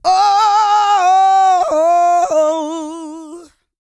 E-GOSPEL 231.wav